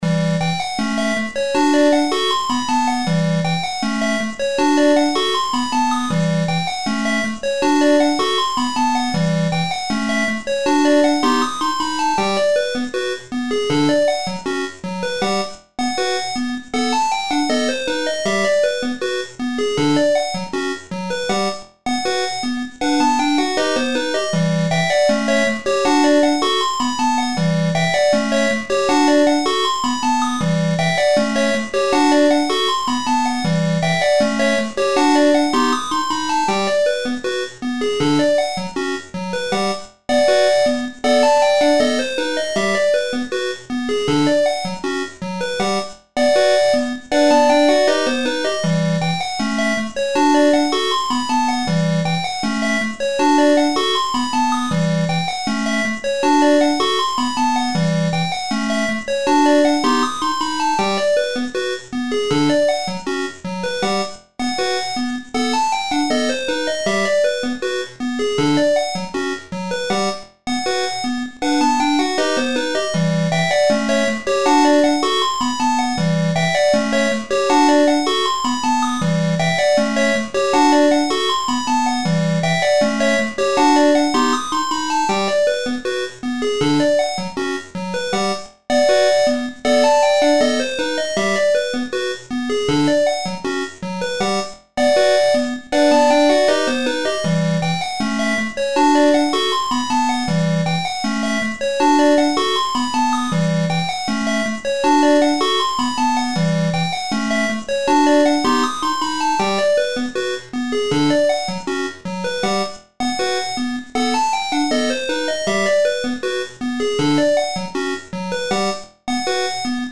LOOP推奨： LOOP推奨
楽曲の曲調： SOFT